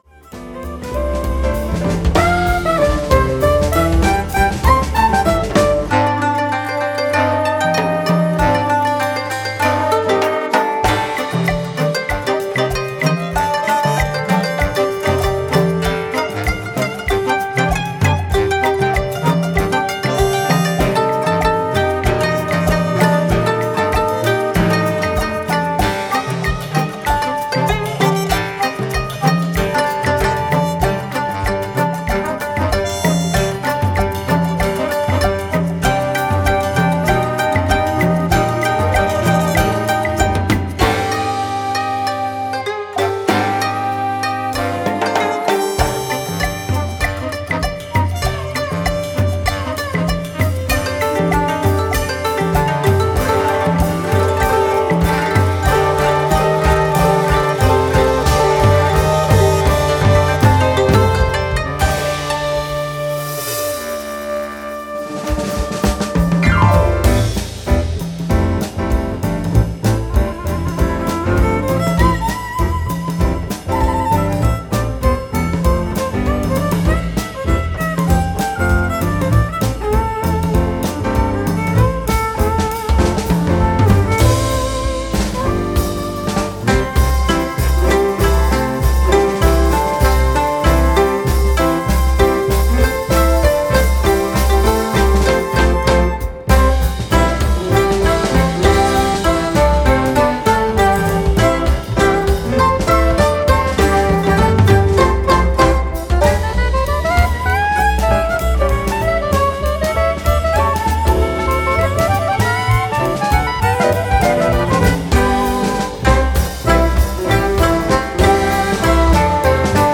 日本唱歌をジャズアレンジ